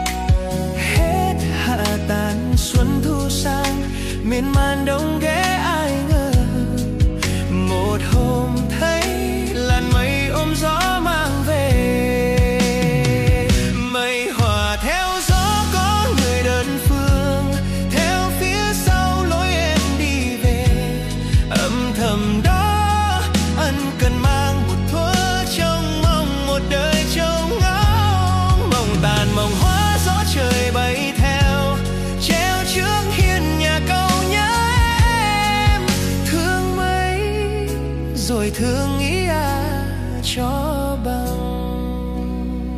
phong cách nhẹ nhàng, chill và đầy cảm xúc
Ca khúc gây ấn tượng bởi phần giai điệu mộc mạc